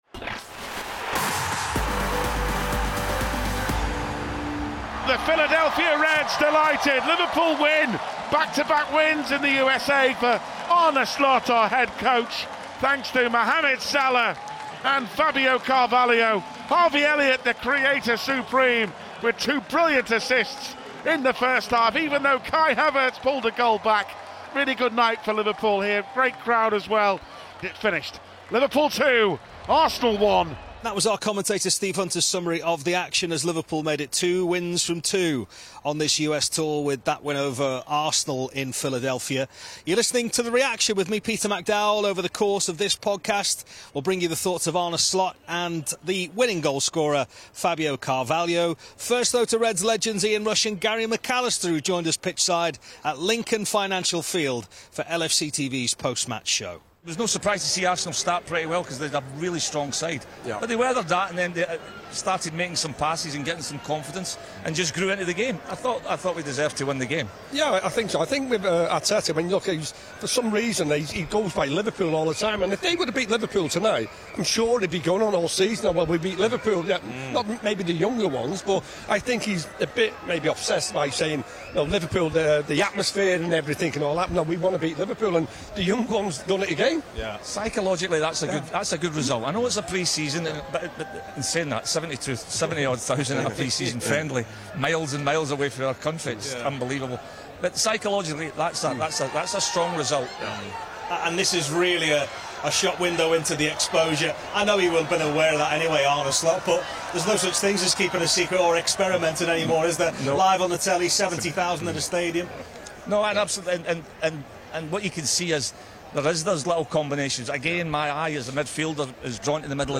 Arne Slot and goalscorer Fabio Carvalho reflect on Liverpool’s 2-1 win over Arsenal in the second match of their US tour. Reds legends Ian Rush and Gary McAllister provide pitchside analysis at Lincoln Financial Field, where Mo Salah was also on the scoresheet.